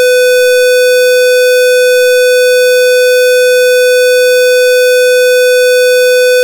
For now I have configured the new codec to be Master and beagle as Slave but the clock from I2S is not syncronized and some noise is generated.
One contains a tone with a squared shape.
square_tone.wav (1.08 MB)